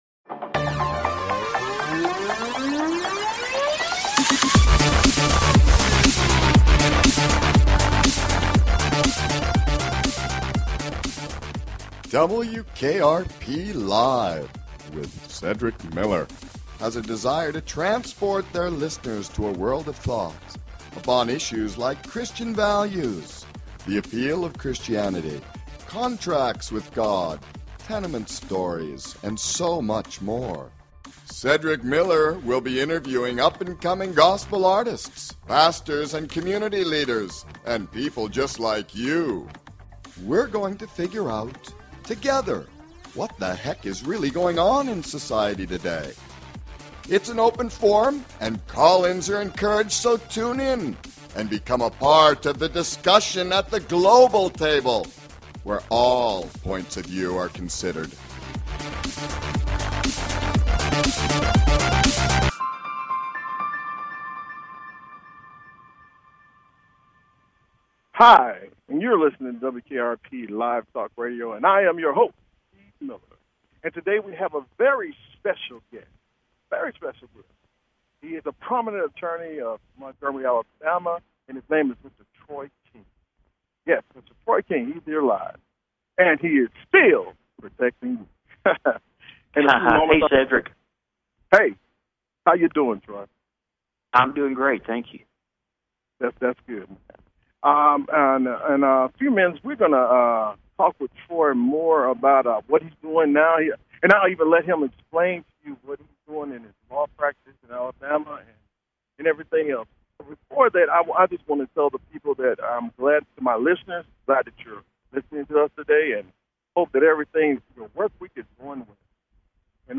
Talk Show Episode
Guest, Troy King